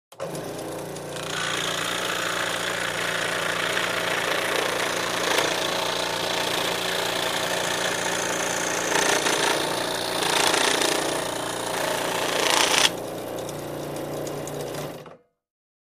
in_scrollsaw_sawing_02_hpx
Scroll saw idles, cuts thin wood and shuts off.